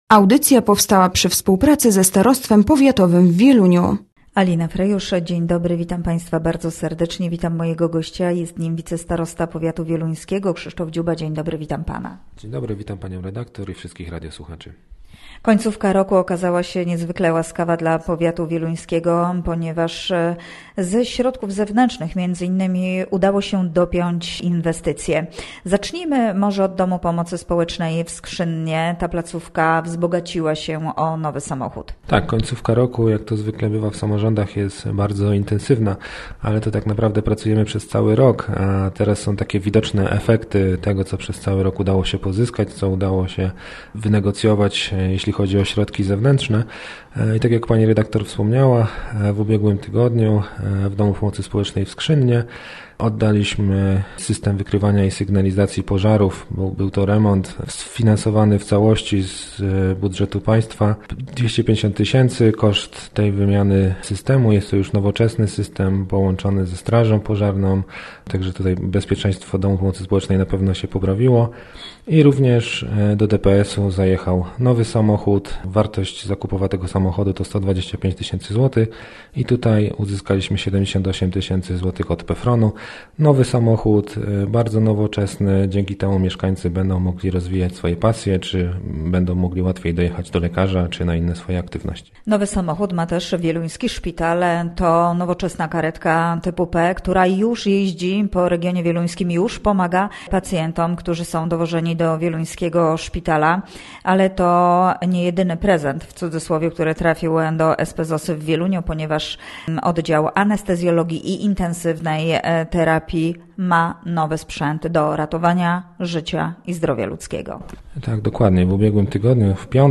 Gościem Radia ZW był wicestarosta wieluński Krzysztof Dziuba